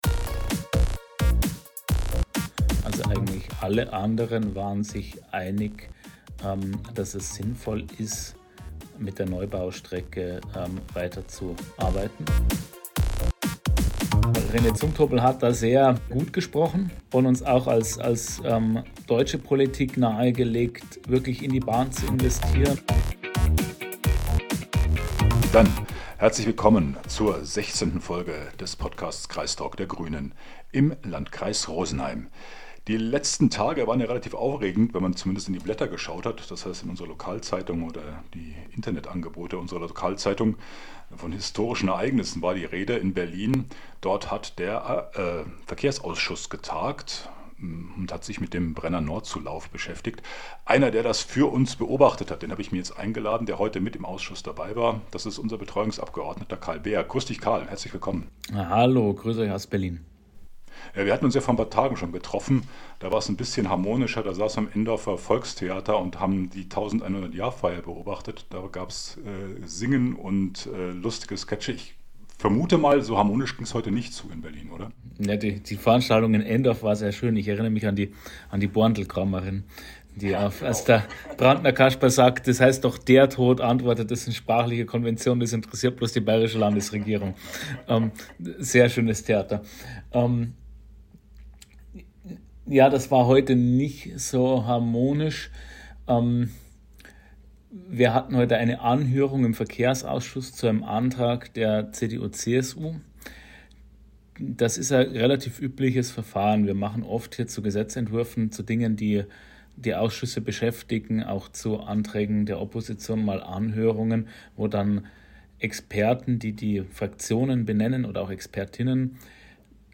Unser Betreuungsabgeordneter berichtet über die Sitzung des Verkehrsausschusses im Bundes Tages. Dort wurde auf Antrag der Opposition über die Ausbaumöglichkeiten des Brennernordzulaufs durch das Inntal diskutiert.